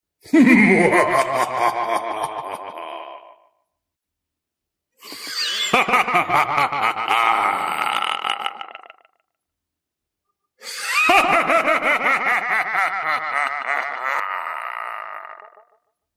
Muahahaha!
muahaha.mp3